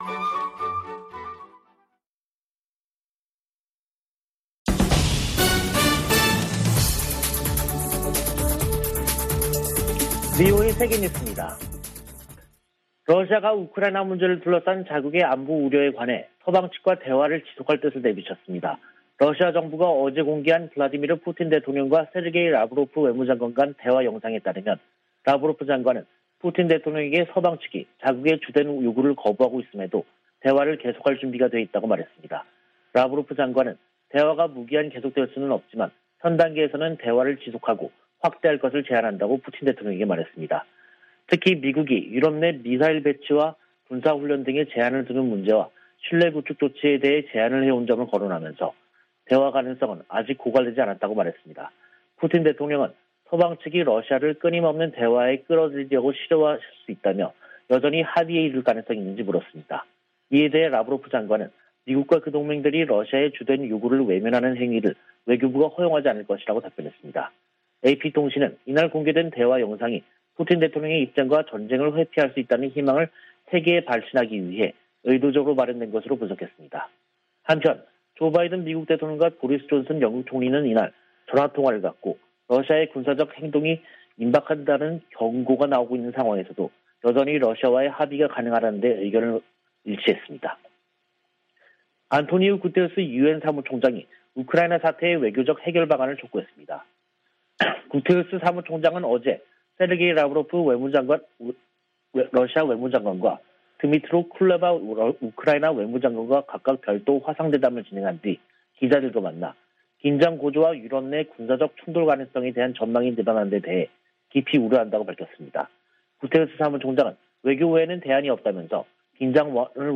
VOA 한국어 간판 뉴스 프로그램 '뉴스 투데이', 2022년 2월 15일 2부 방송입니다. 미 국무부는 북한 영변 핵 시설이 가동 중이라는 보도에 대해 북한이 비확산 체제를 위협하고 있다고 비판했습니다. 조 바이든 미국 대통령이 물러날 때 쯤 북한이 65개의 핵무기를 보유할 수도 있다고 전문가가 지적했습니다. 미한일이 하와이에서 북한 문제를 논의한 것과 관련해 미국의 전문가들은 3국 공조 의지가 확인됐으나, 구체적인 대응이 나오지 않았다고 평가했습니다.